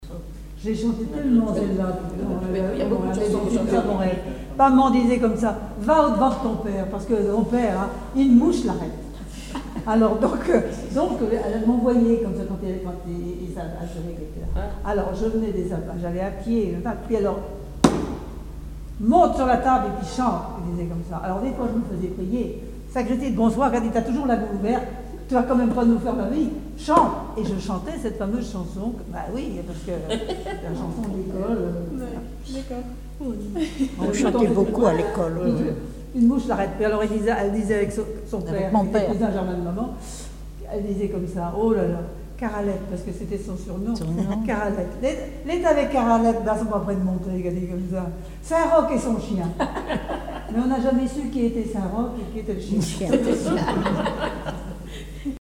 Localisation Île-d'Yeu (L')
Chansons et formulettes enfantines
Catégorie Témoignage